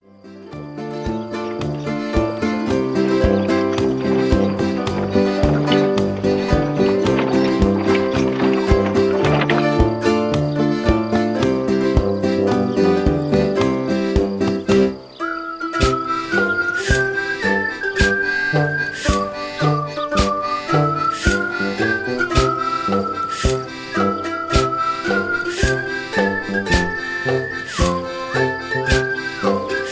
tanzen fröhlich.